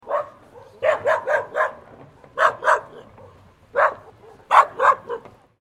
Dog Sounds - Listen or Free Download MP3 | Orange Free Sounds
Medium-dog-barking-sound-effect.mp3